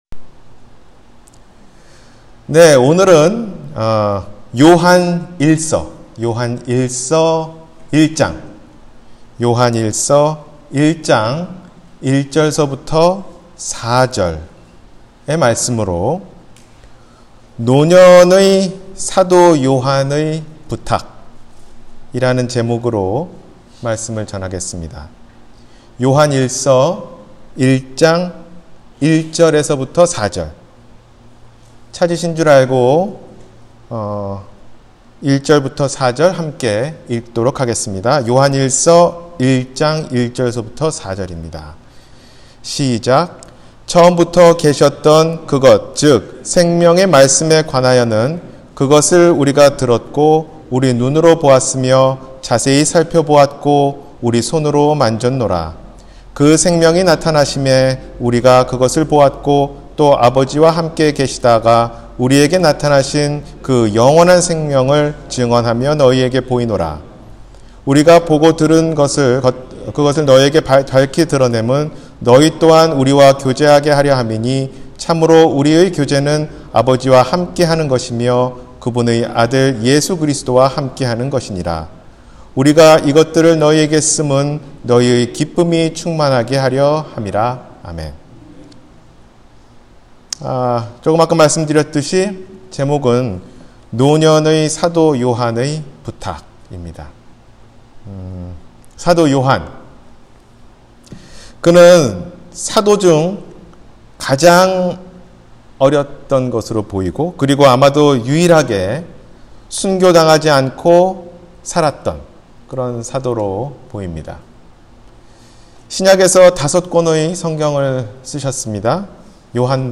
노년의 사도 요한의 부탁 – 주일설교